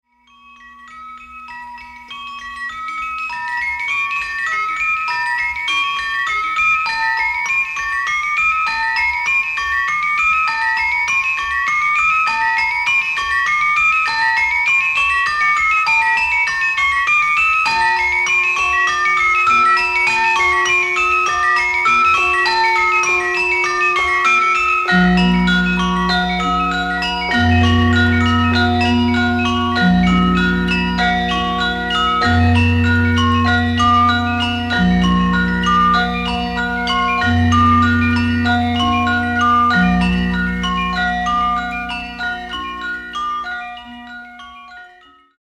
ガムランの民族的属性を排除し、音具として再発見。
まるで電子音のようにも聴こえる淡〜い響きに昇天必至！
キーワード：ガムラン　ミニマル　創作楽器